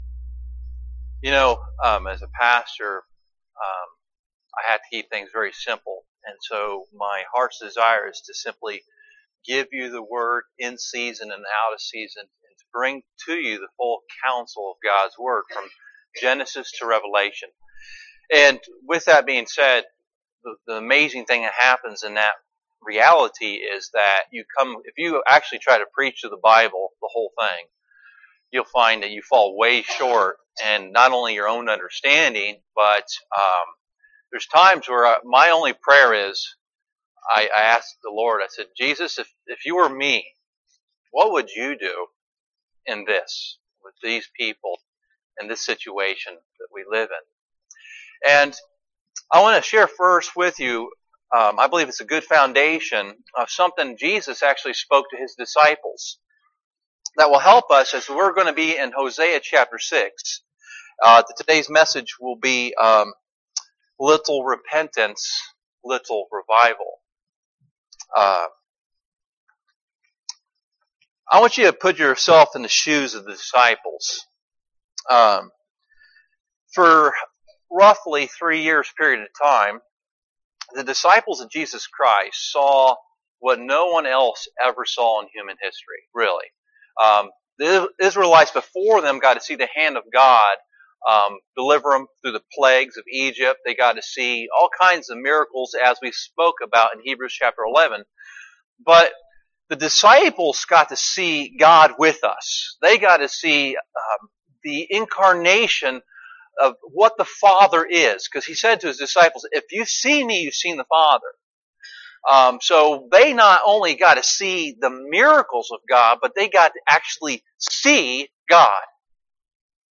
Hosea 6 Service Type: Sunday Morning David Wilkerson